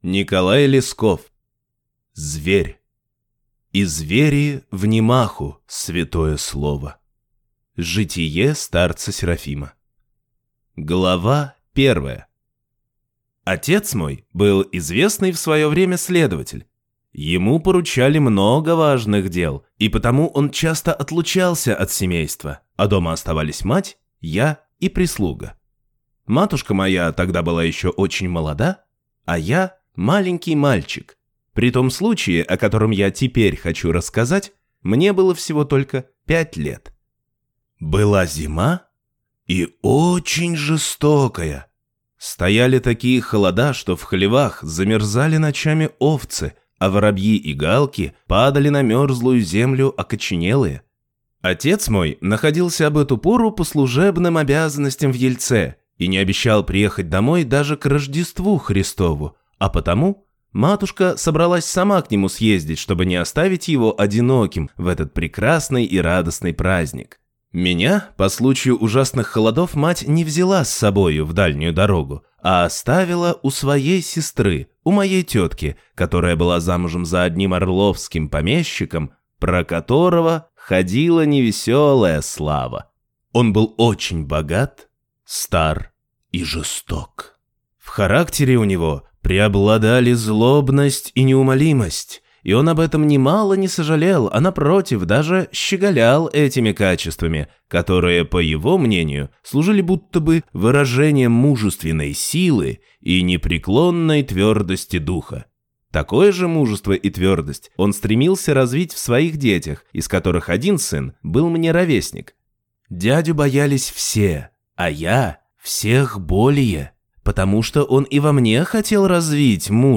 Aудиокнига Зверь